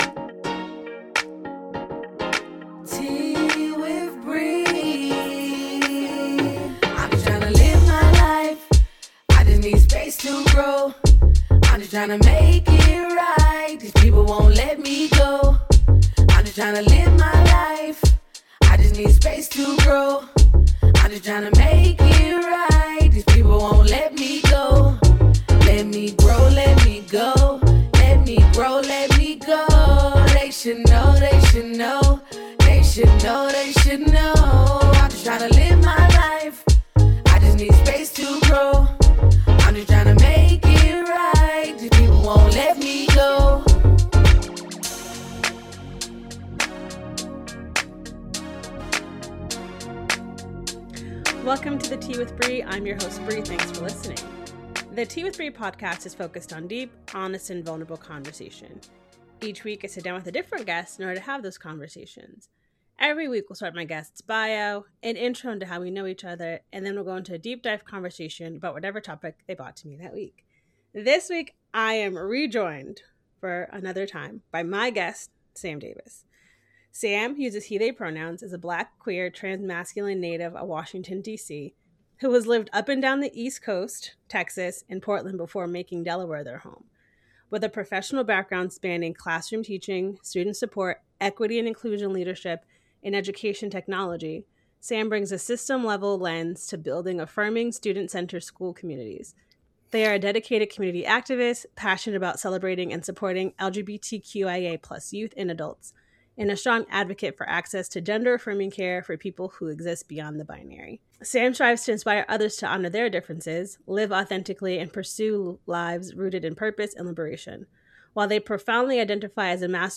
----- This podcast was recorded via Riverside FM.